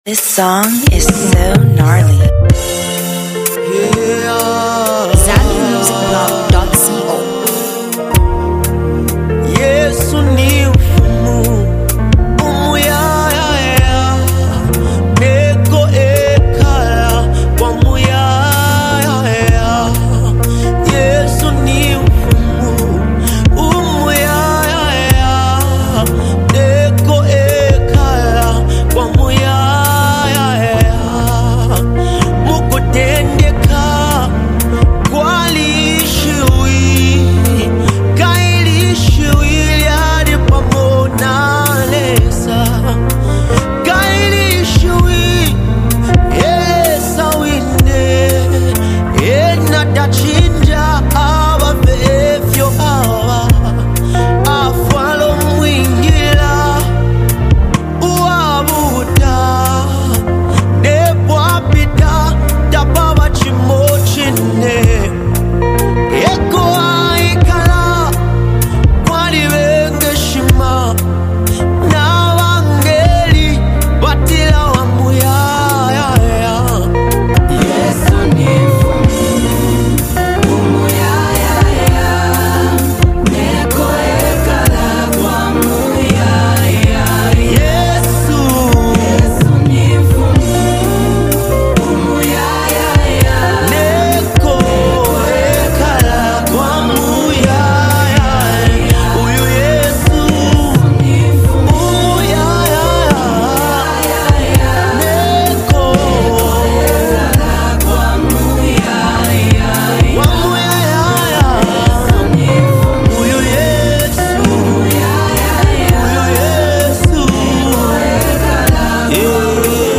soul-stirring musical masterpiece